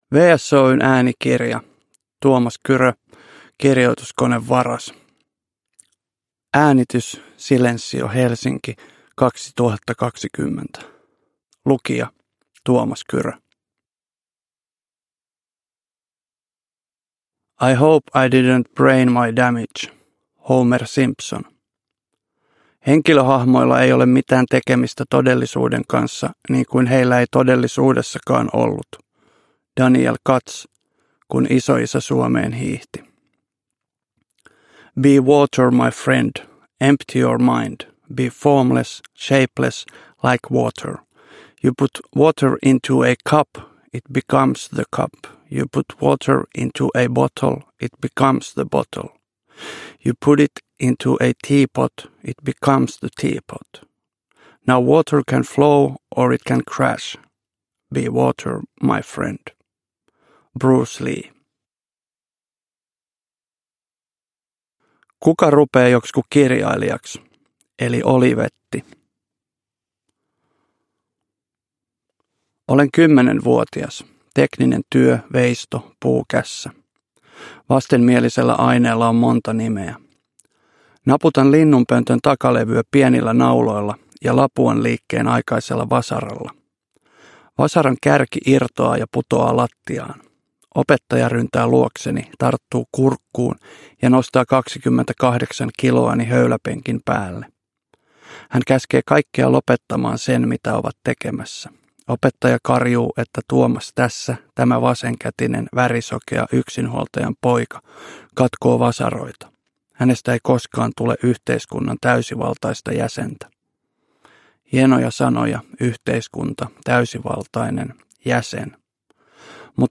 Kirjoituskonevaras – Ljudbok – Laddas ner
Uppläsare: Tuomas Kyrö